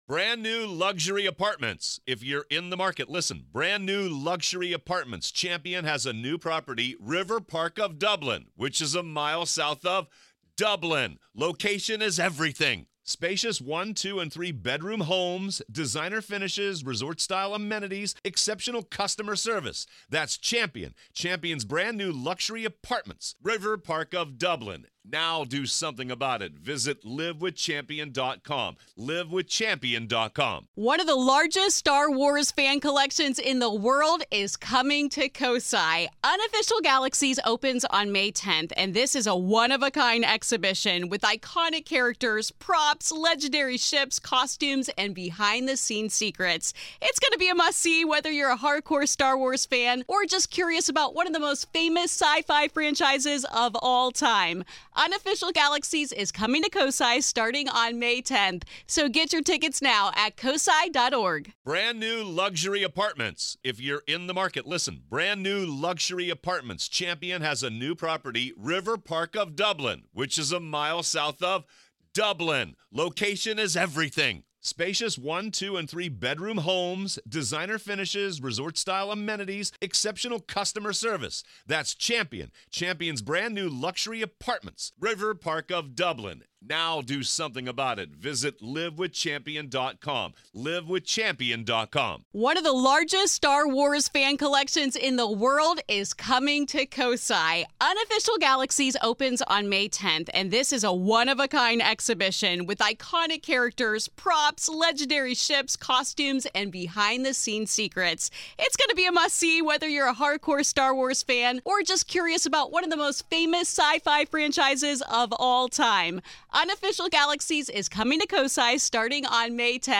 This is Part One of our conversation.